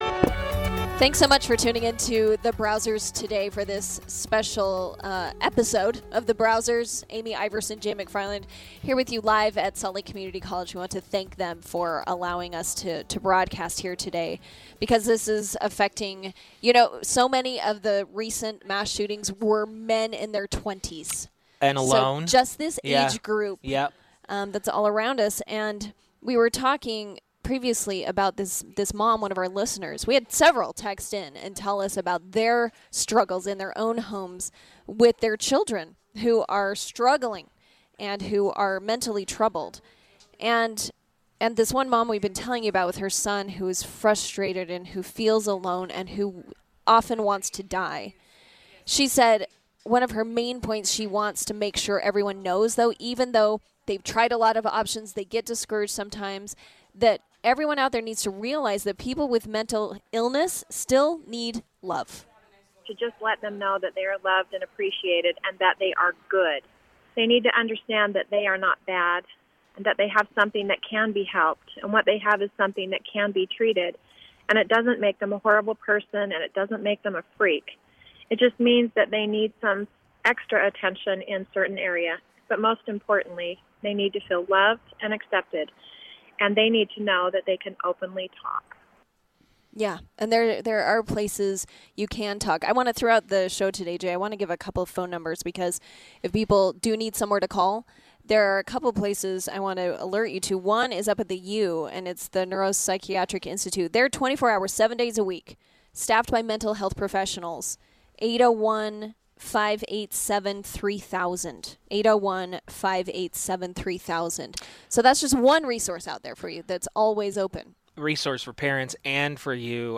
The Browsers special broadcast from Salt Lake Community College in the aftermath of the UCC shooting.